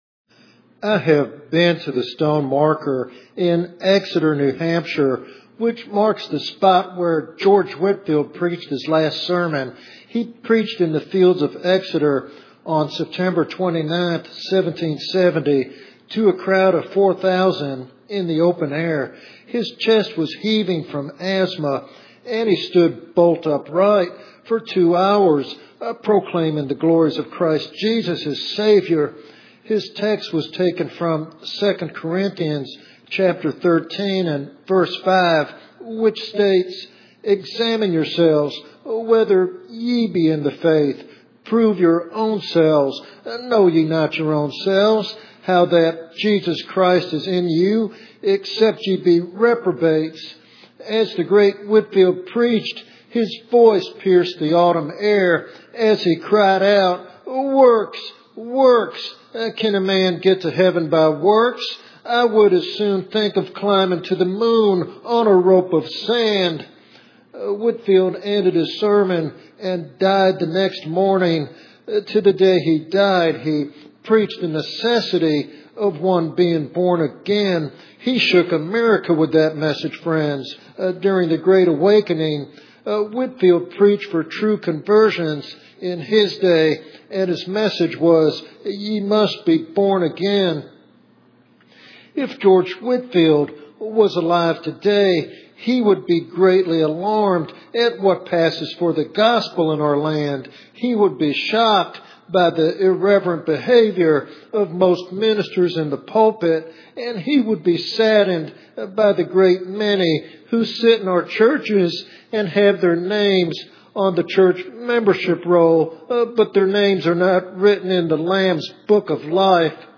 He calls the church to return to a gospel that demands a transformed life and warns of the peril of resting on a false foundation. This sermon serves as a solemn wake-up call to those who may be deceived by an easy-believed gospel.